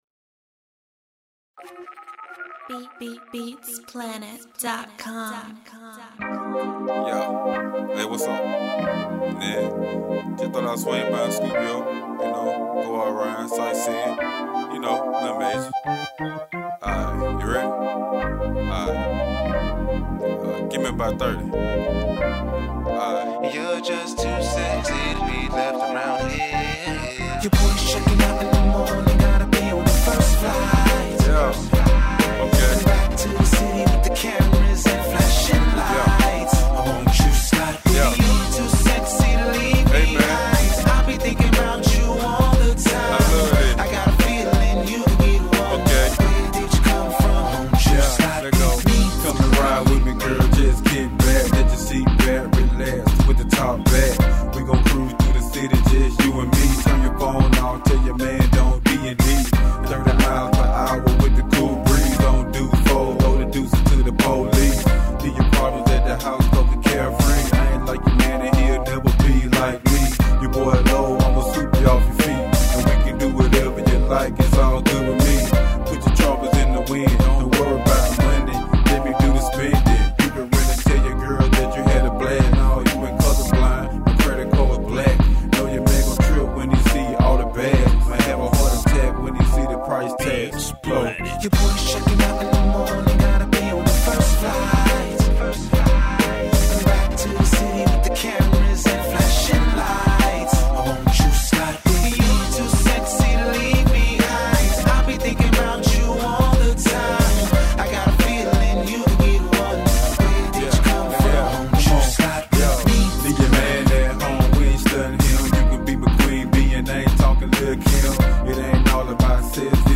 Rap
Hip-hop
Club Anthems